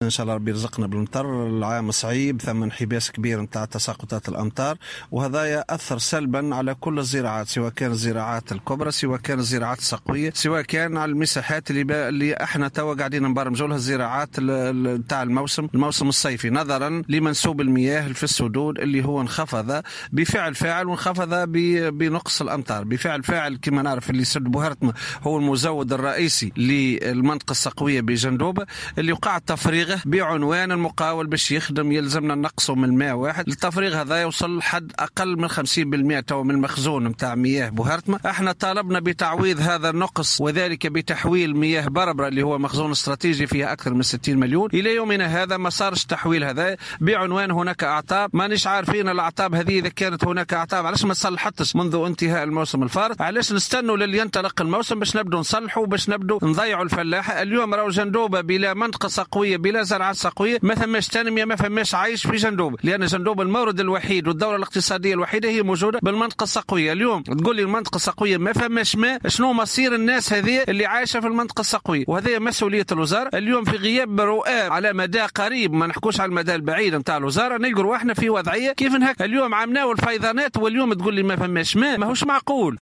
وأوضح في تصريح اليوم لمراسل "الجوهرة أف أم" أنه على الرغم من النقص الحاد في كميات الأمطار وما له من تأثيرات سلبية على الزراعات الكبرى والزراعات السقوية، إلا أنه تم إفراغ سدّ بوهرتمة، المزوّد الرئيسي للمنطقة السقوية في الجهة والتقليص من مخزون المياه "بتعلّة القيام بأشغال".